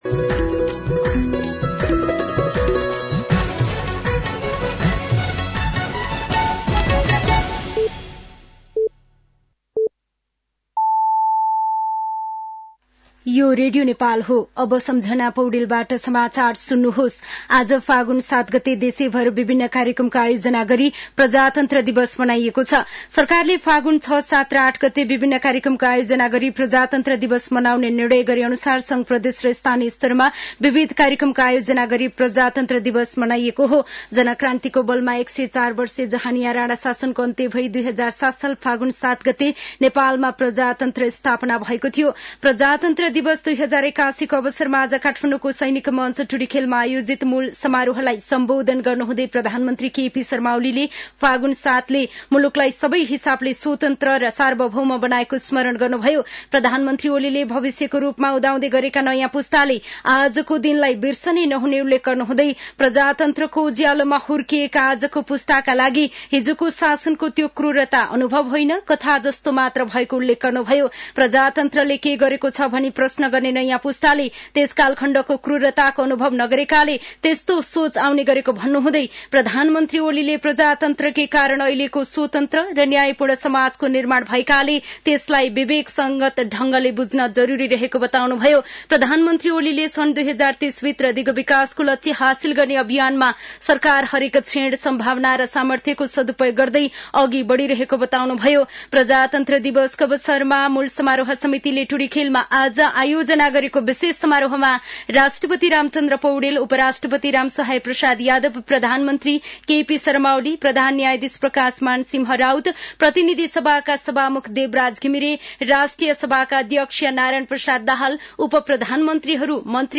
An online outlet of Nepal's national radio broadcaster
साँझ ५ बजेको नेपाली समाचार : ८ फागुन , २०८१